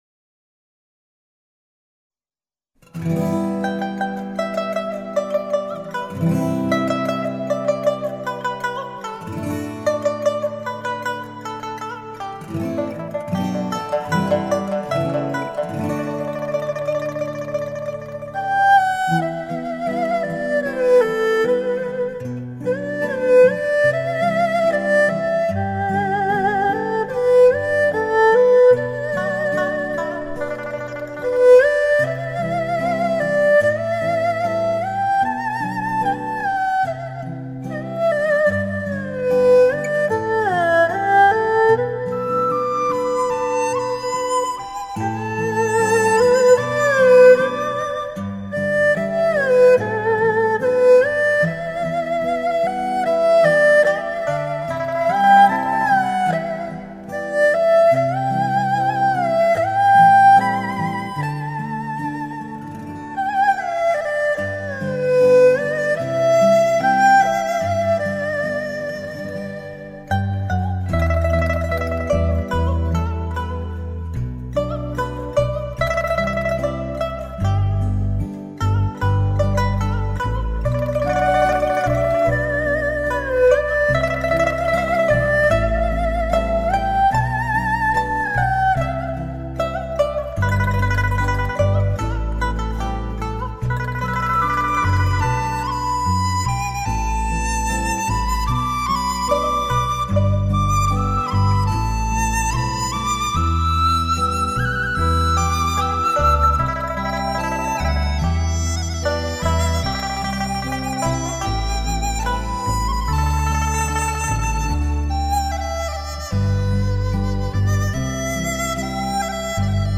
风格甚为古朴、儒雅
二胡音像“形态”丰满厚实，音色甜润醇和而且胆味浓郁，质感之真实犹如亲临录音现场。